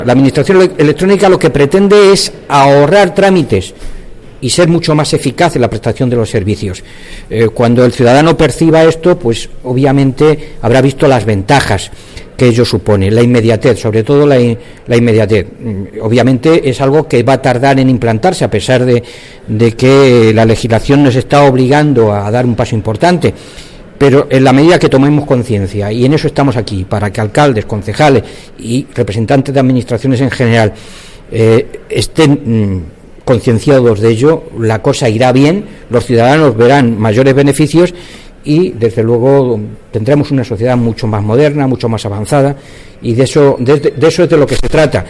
El viceconsejero de Administración Local y Coordinación Administrativa en las jornadas de Administración Electrónica de Guadalajara